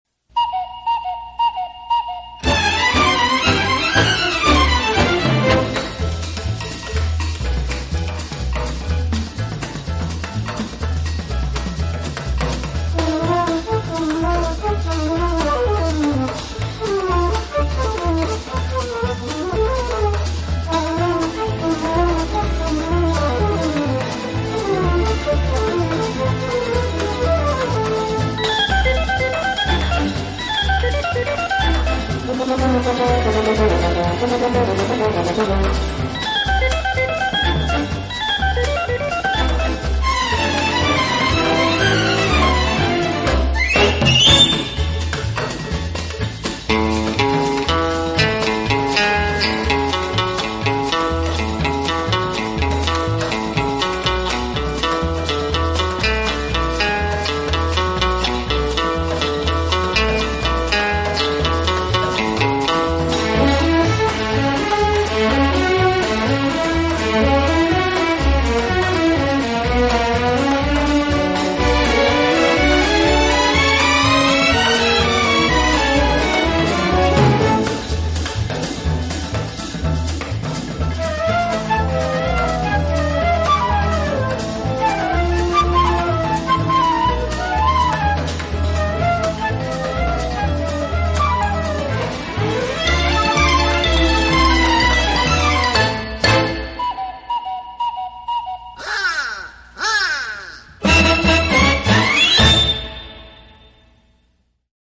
【轻音乐专辑】
音乐奖，其歌曲作品小巧玲珑，大都显示一种精致的通俗音乐的曲风。